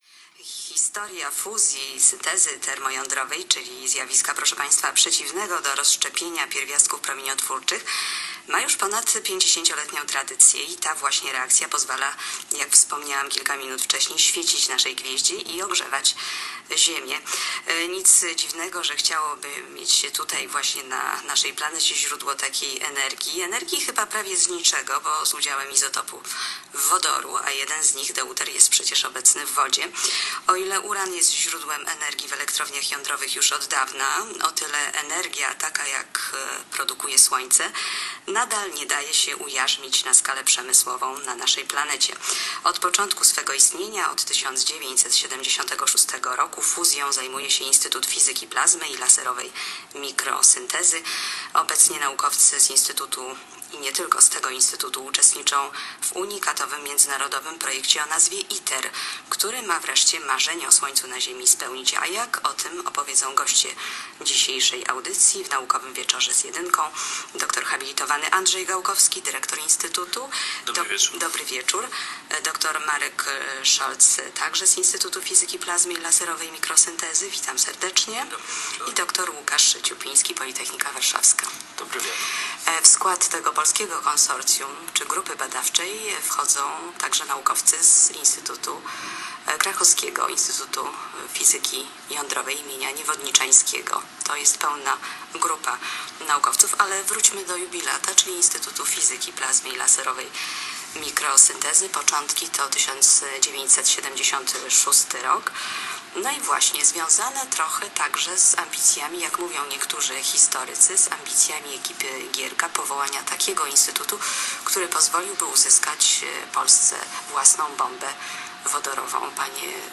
Program I Polskiego Radia - wywiad